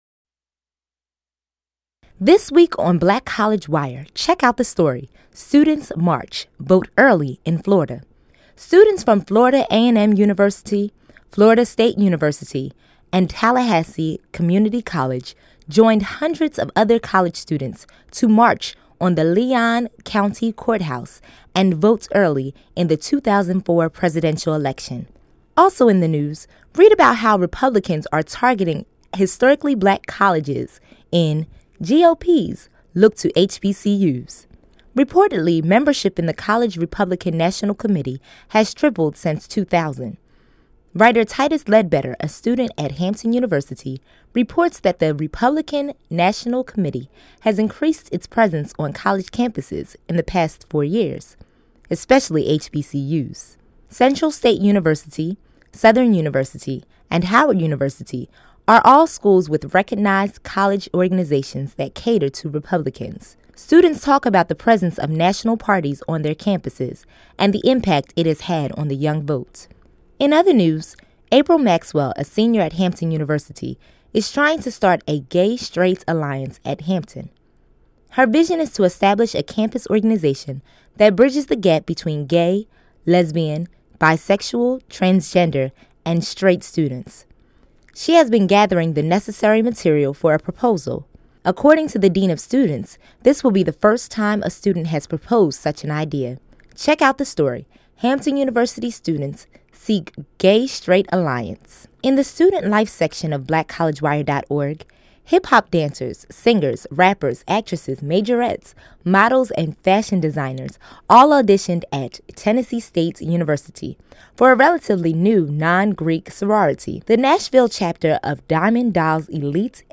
News Summary of the Week